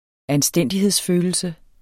Udtale [ anˈsdεnˀdiheðs- ]